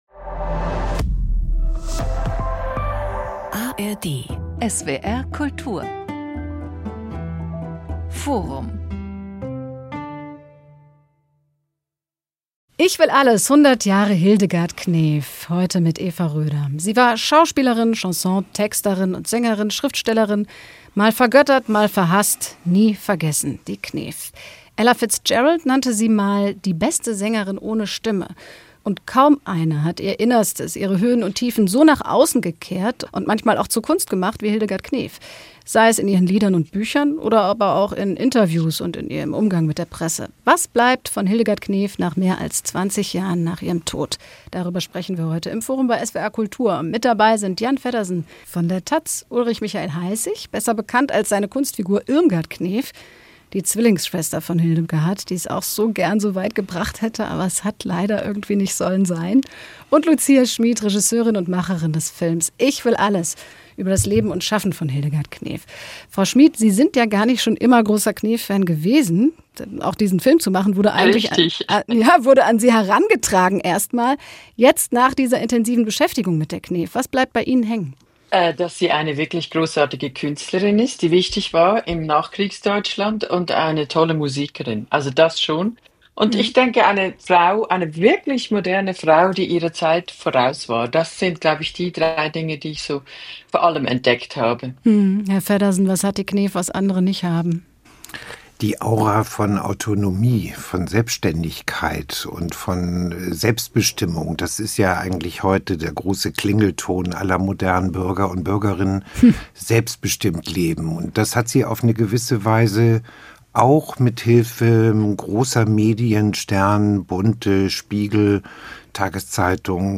Kabarettist
Dokumentarfilmerin